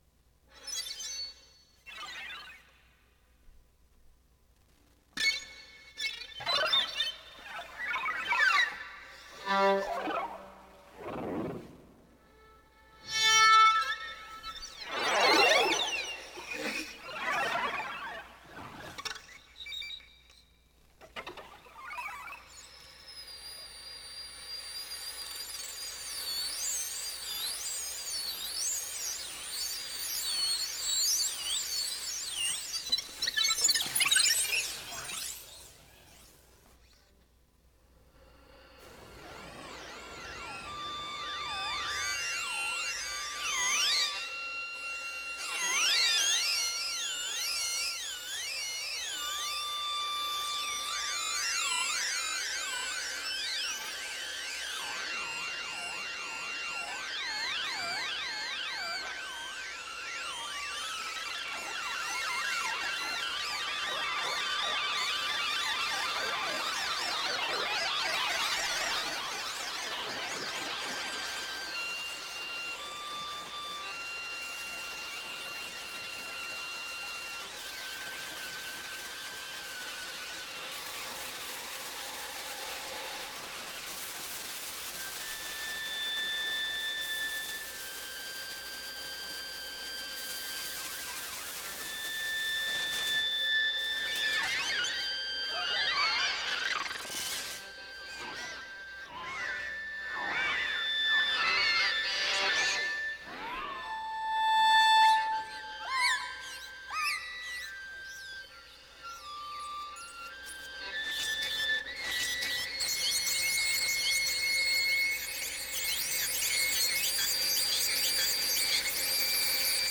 A special live stream and subsequent broadcast of...
violinist
electro-acoustic musical encounters